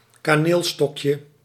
Ääntäminen
US : IPA : /ˈpi.nɪs/ UK : IPA : /ˈpiː.nɪs/